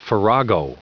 Prononciation du mot farrago en anglais (fichier audio)
Prononciation du mot : farrago